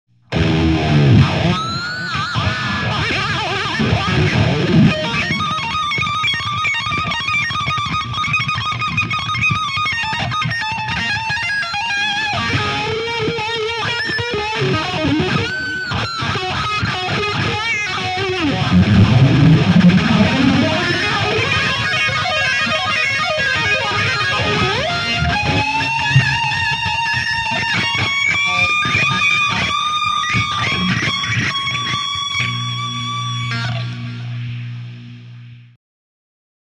Соляки